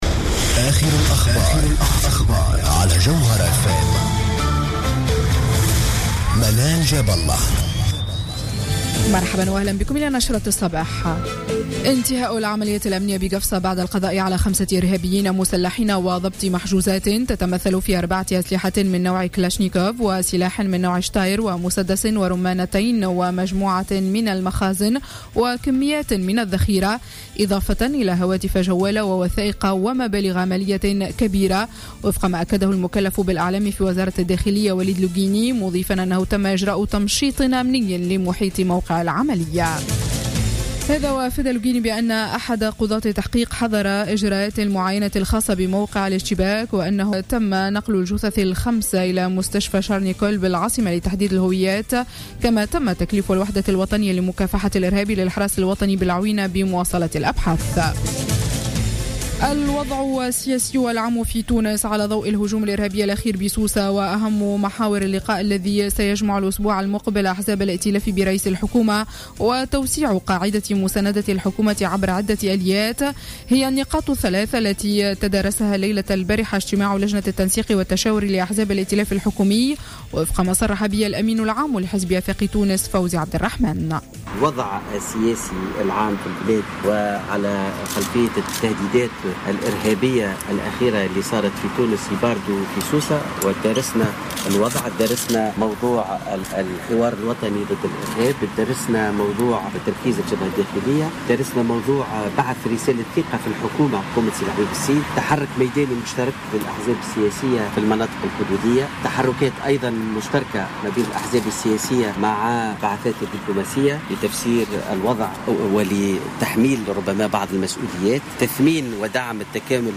نشرة أخبار السابعة صباحا ليوم السبت 11 جويلية 2015